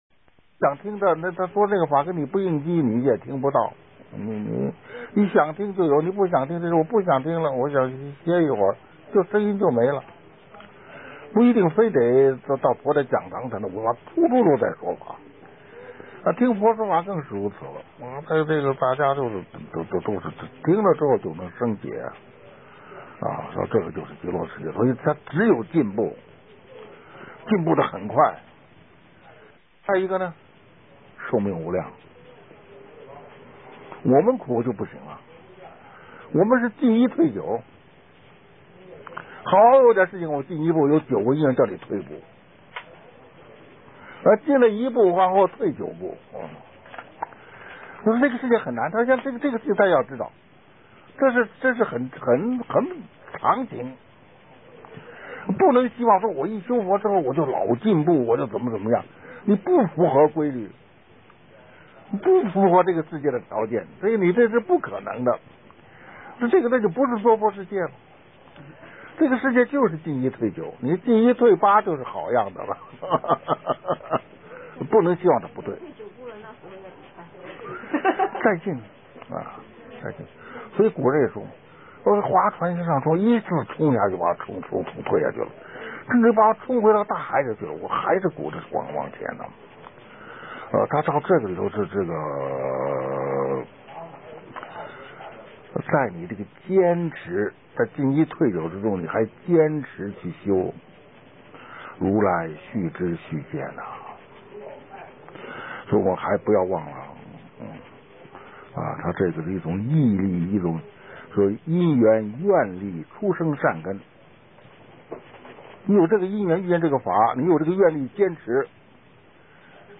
佛學講座-聲音檔